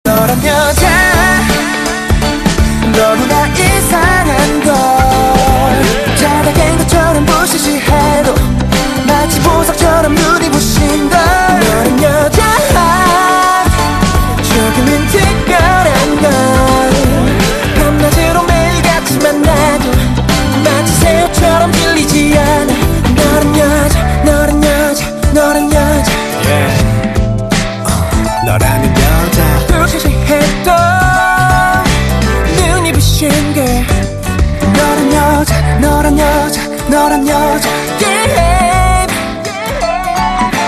M4R铃声, MP3铃声, 日韩歌曲 95 首发日期：2018-05-15 01:53 星期二